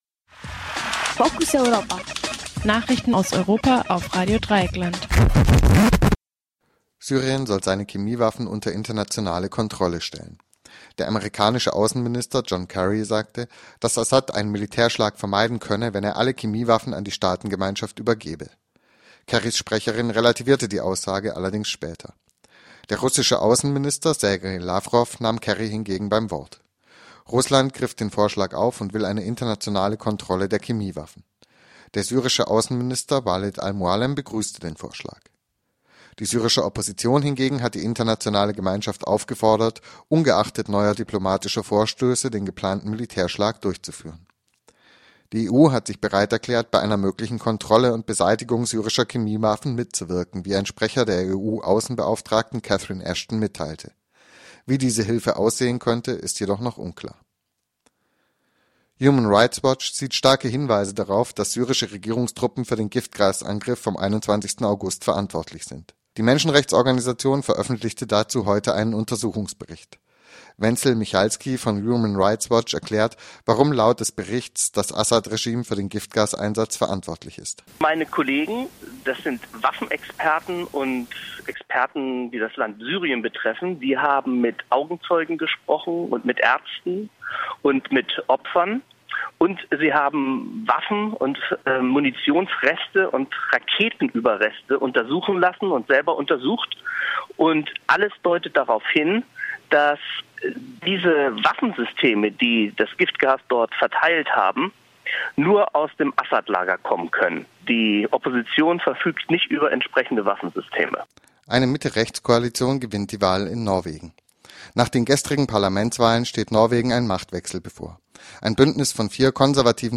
Focus Europa Nachrichten vom Dienstag, den 10. September 2013 - 18 Uhr